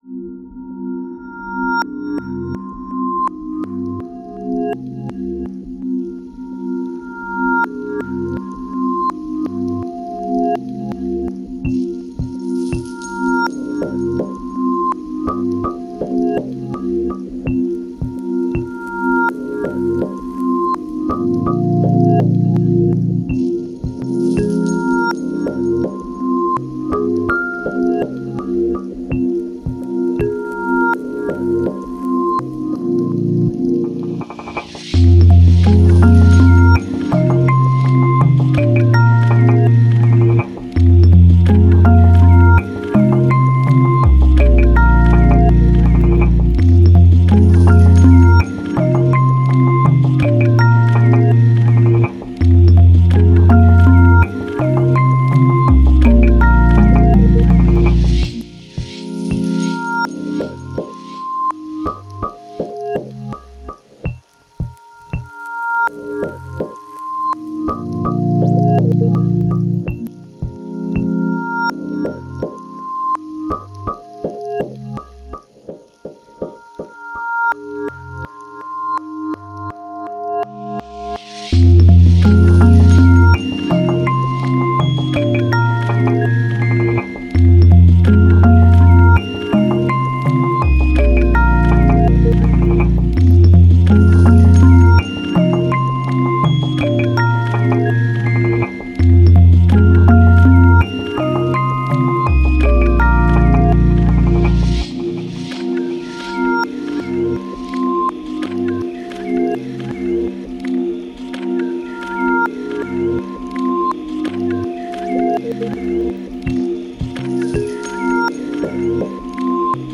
Wildlife dreams wrapped up in delicate electronic texture.